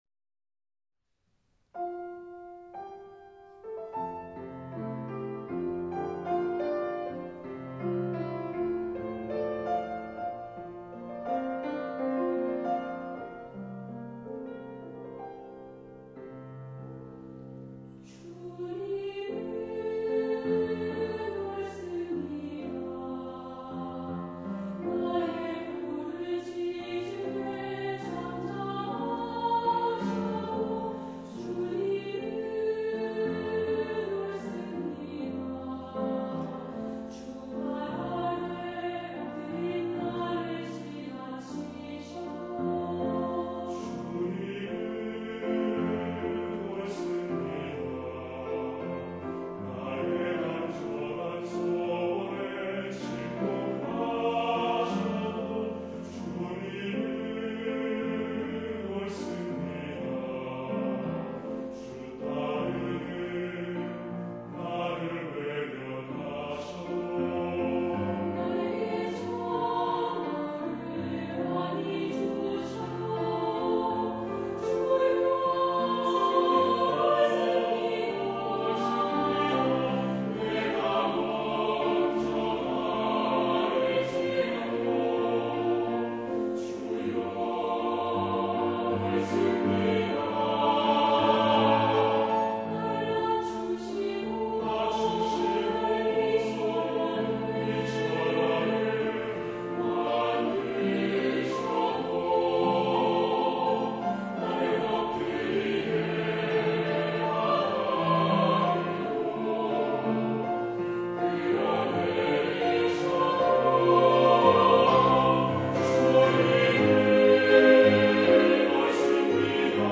예사랑찬양대